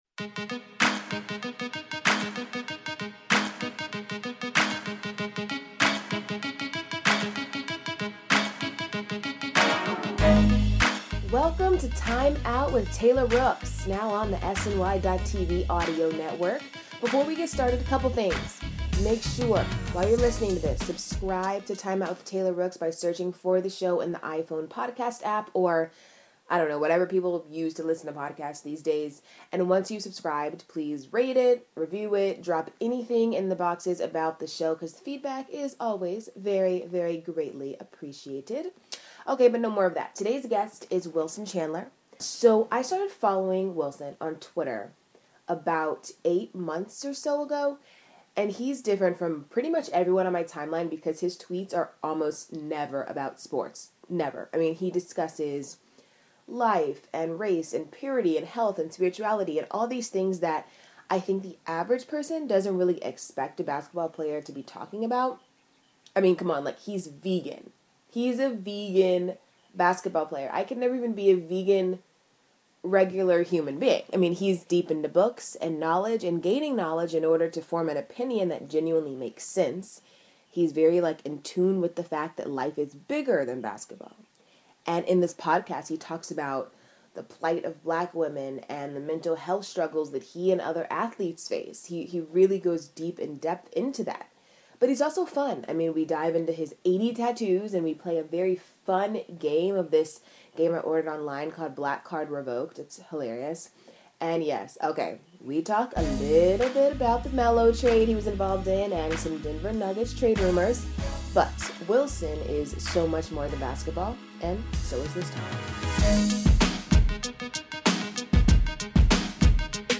SNY’s Taylor Rooks sits down with Denver Nuggets forward Wilson Chandler to discuss his wide array of tattoos, TV, cooking, the challenges black women face in today’s society, and Carmelo Anthony.